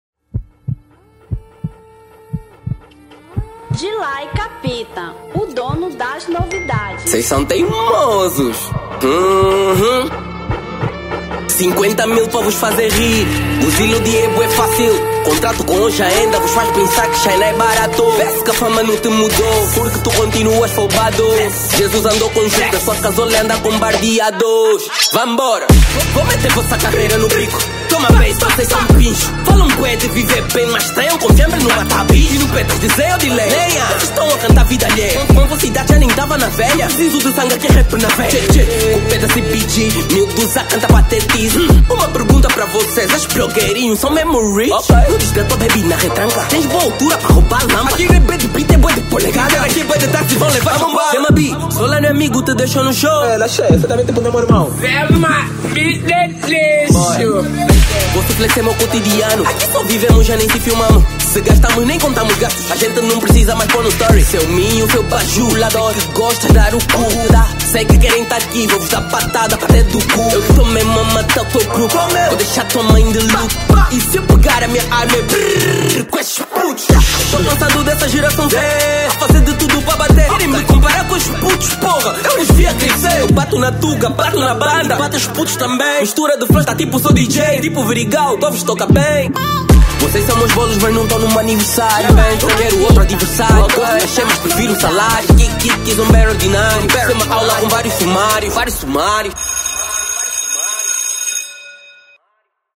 Trap 2025